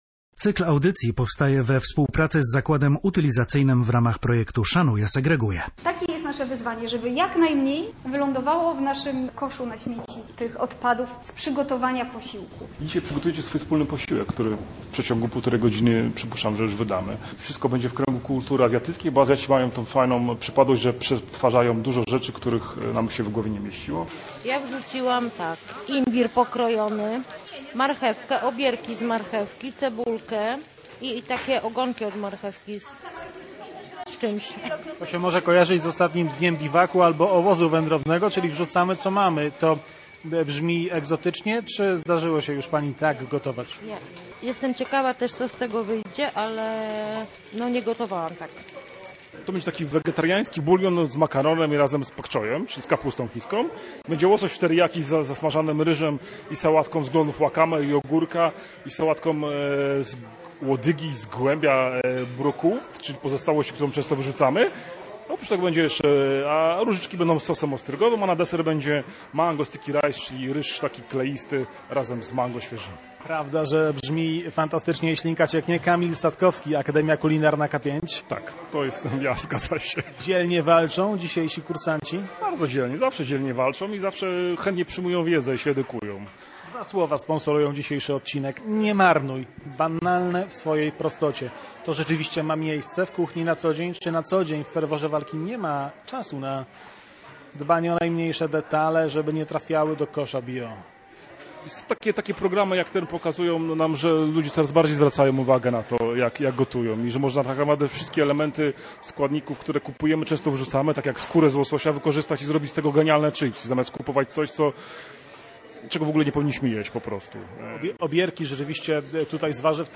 Zapraszamy państwa na relację z niecodziennych warsztatów kulinarnych: